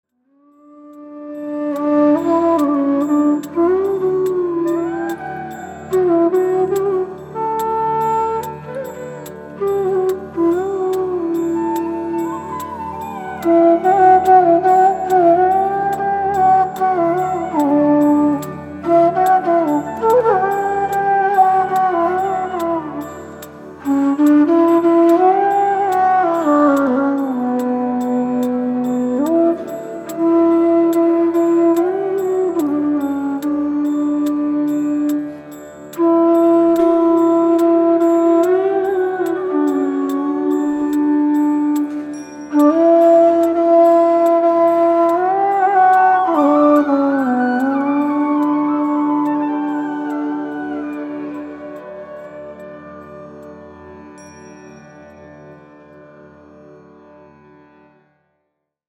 Genre: World Fusion.
bansuri and manjeera
conch
This is a melody of a traditional Hindu hymn.